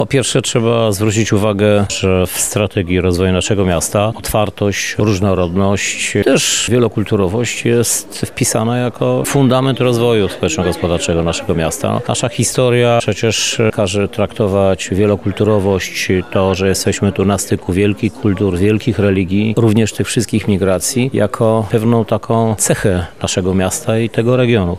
Na Wydziale Filozofii i Socjologii UMCS spotkali się przedstawiciele administracji publicznej, środowiska eksperckiego oraz sektora społecznego w obszarze zarządzania migracją.
Krzysztof Żuk– mówi Krzysztof Żuk, Prezydent Miasta Lublin.